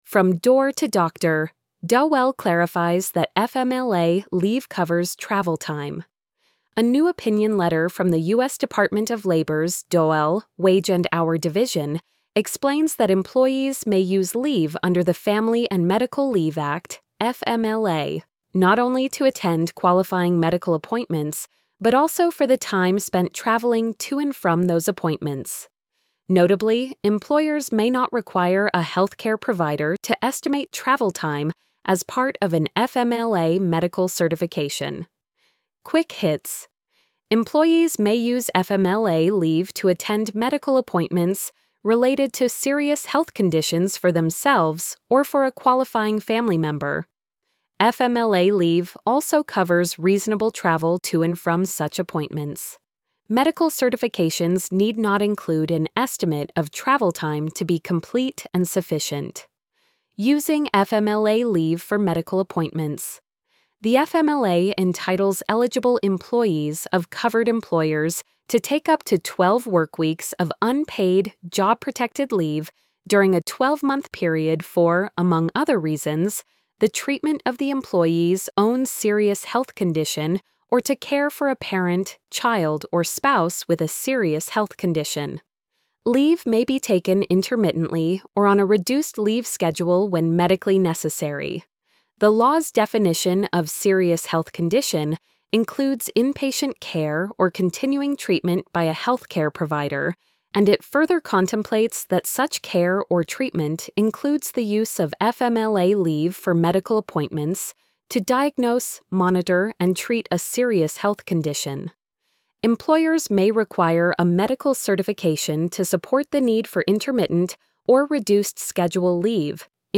from-door-to-doctor-dol-clarifies-that-fmla-leave-covers-travel-time-tts.mp3